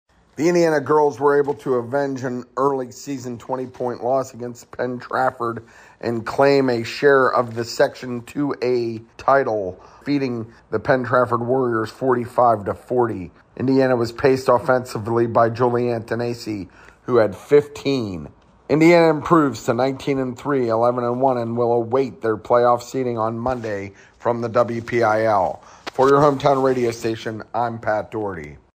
GIRLS BASKETBALL